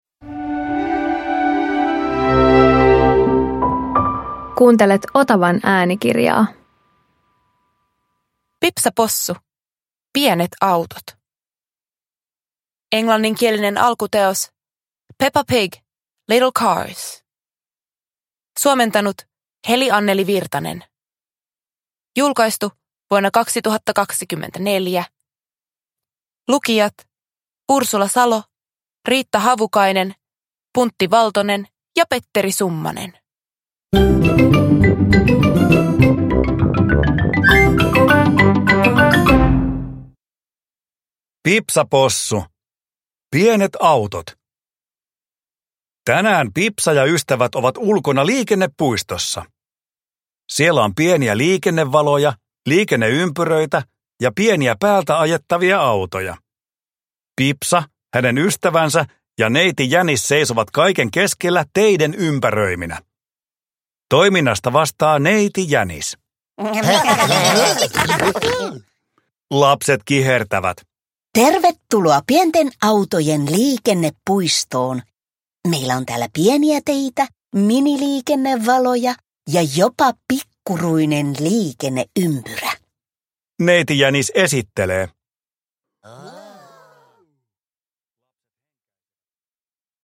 Pipsa Possu - Pienet autot – Ljudbok